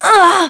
Aselica-Vox_Damage_02.wav